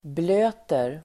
Uttal: [bl'ö:ter]